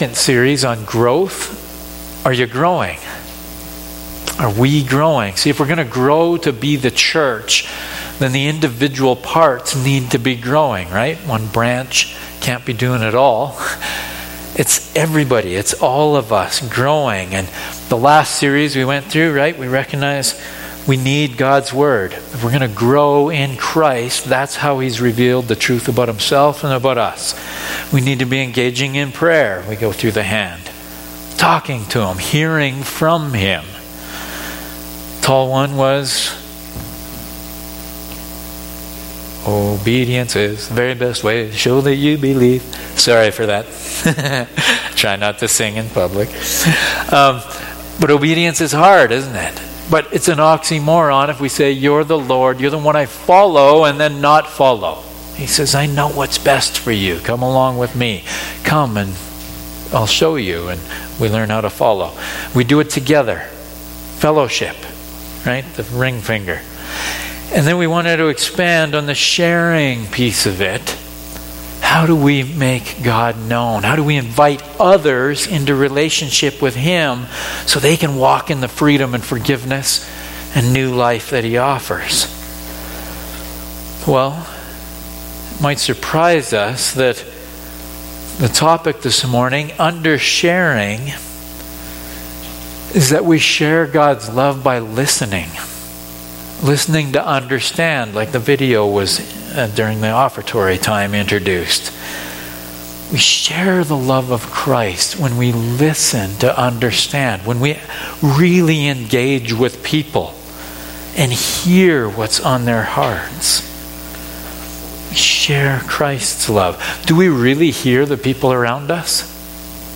Sermons | Coaldale MB Church